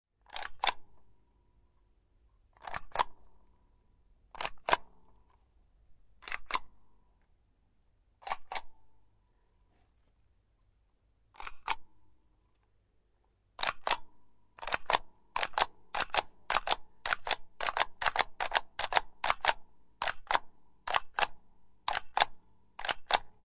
印章
描述：邮票声。用Behringer C4和Focusrite Scarlett 2i2录制。
Tag: 冲压 声音 印花税